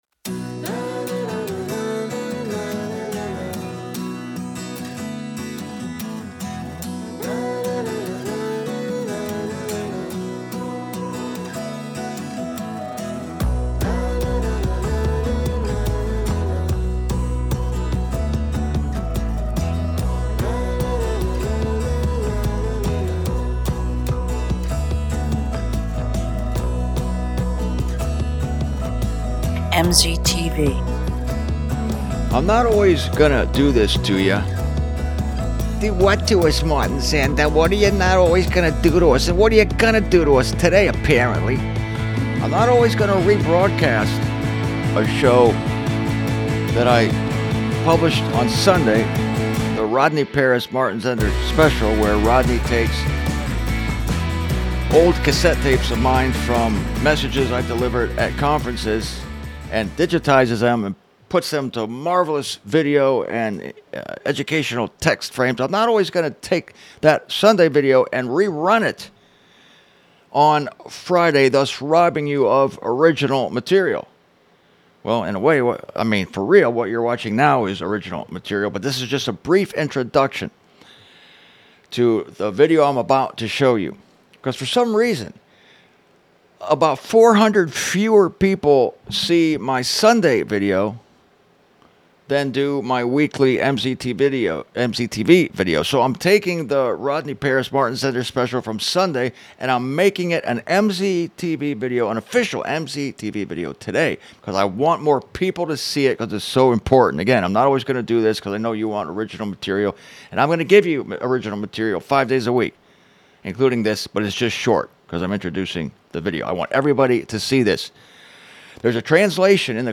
In this video—digitized from a cassette tape audio recording of an address I gave in Grand Rapids, Michigan, back in 1996—I analyze every single occurrence of "rhipto" in its Scriptural context to show you that not only is "twinkle" ridiculous, it hides a startling truth concerning our snatching away that, really, you need to hear.